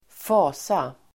Uttal: [²f'a:sa]